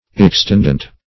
extendant - definition of extendant - synonyms, pronunciation, spelling from Free Dictionary Search Result for " extendant" : The Collaborative International Dictionary of English v.0.48: Extendant \Ex*tend"ant\, a. (Her.)